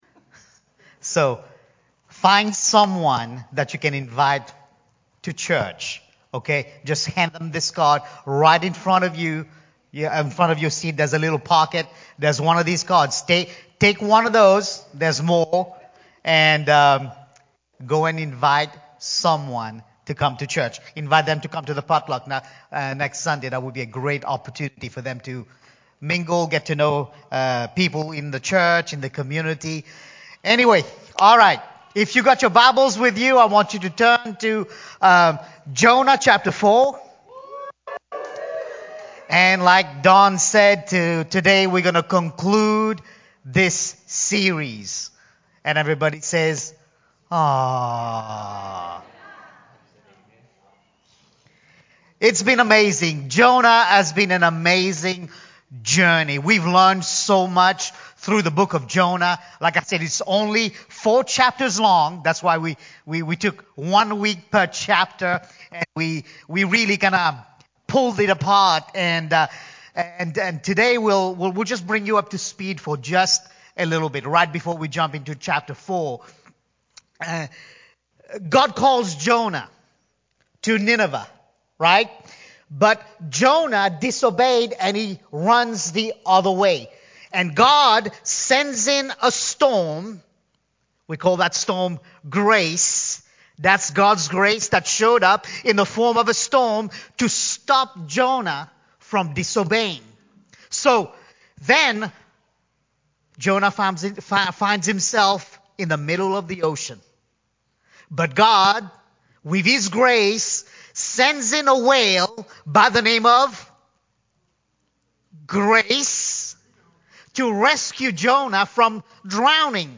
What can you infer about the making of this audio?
Sunday-morning-9_11_22-CD.mp3